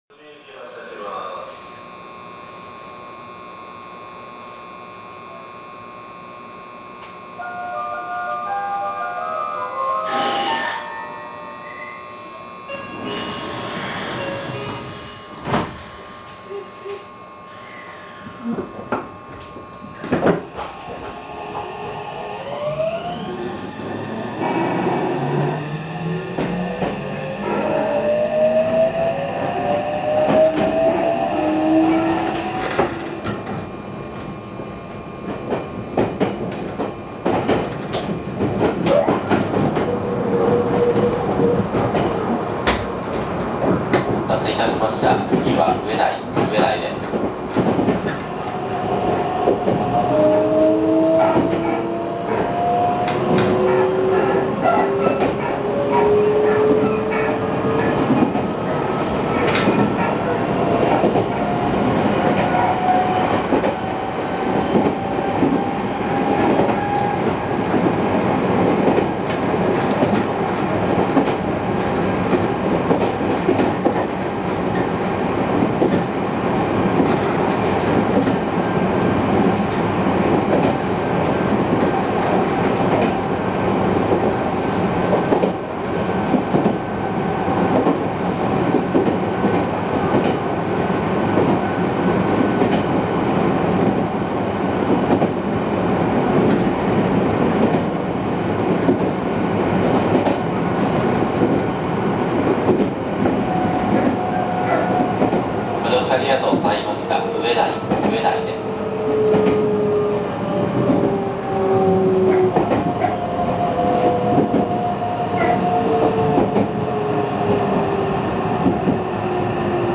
・3150系・3300系(三菱IGBT)走行音
【河和線】阿久比〜植大（2分20秒：1.07MB）…3301Fにて
初期の三菱IGBTとしてはありがちな、急に音が上がるモーター音となります。各地の路面電車、都営6300形、小田急3000形の初期の編成などが類似のモーター音です。